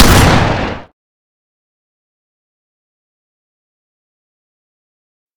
gunshotshotgunshot.ogg